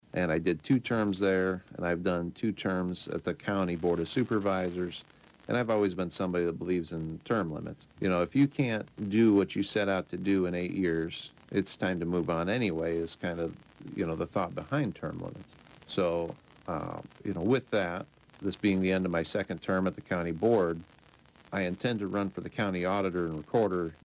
RADIG, WHO IS ALSO A FORMER SIOUX CITY COUNCILMAN EXPLAINED HIS DECISION ON KSCJ’S “OPEN LINE” TUESDAY: